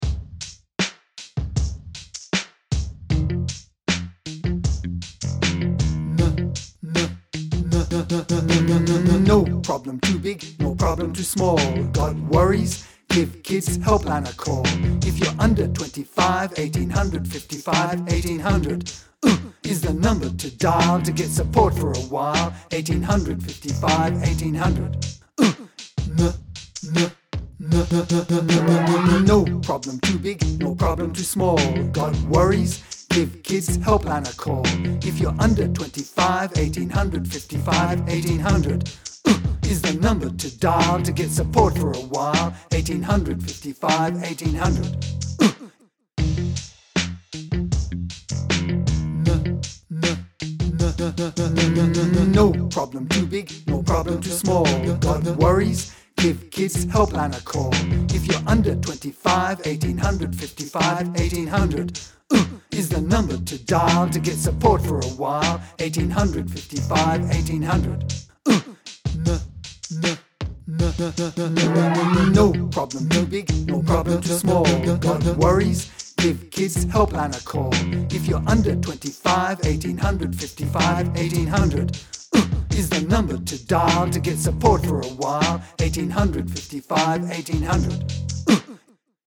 'RELATIONSHIPS - Learning Activities 'Take care' - a song about being assertive and safe: 'Take Care' Audio 'Take Care' Resources - Support Services 'Kids Helpline Rap', - a rap giving contact details for Kids Helpline